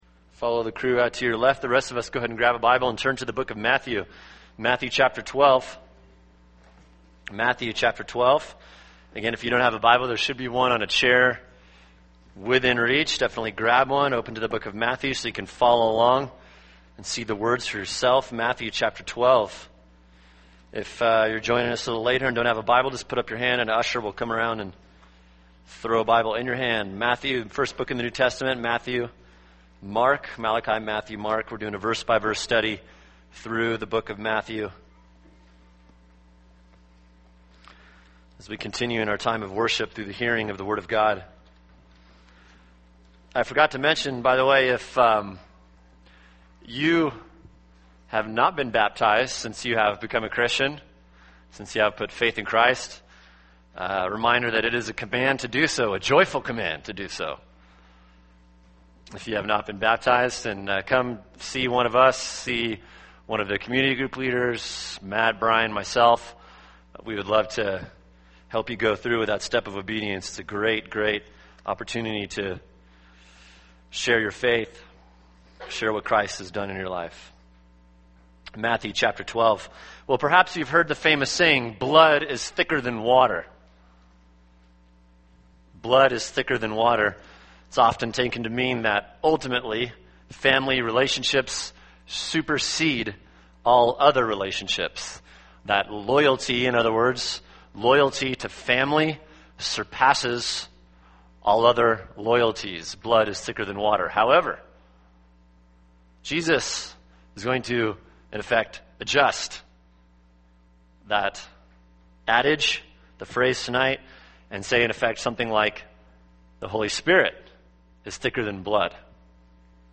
[sermon] Matthew 12:46-50 – Who is Jesus’s Family?